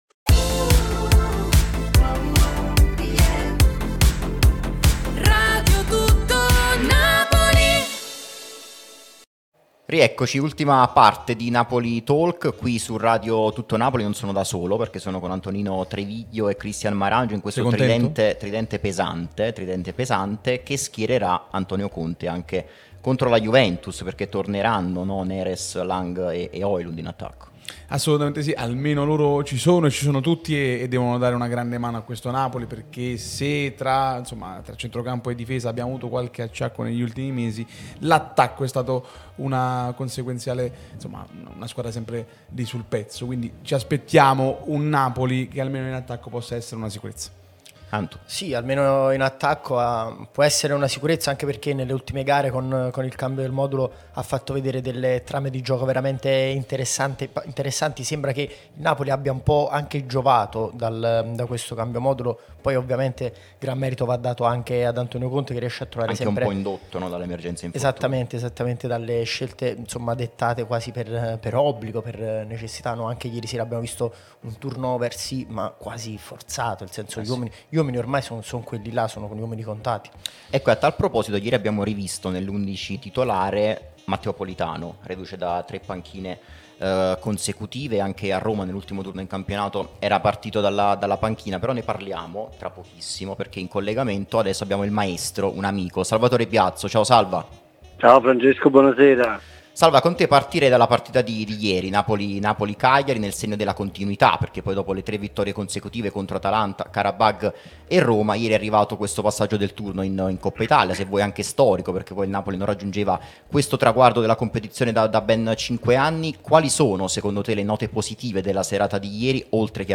Radio TN